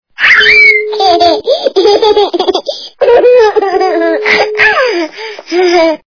» Звуки » звуки для СМС » Детский голос - СМС
При прослушивании Детский голос - СМС качество понижено и присутствуют гудки.
Звук Детский голос - СМС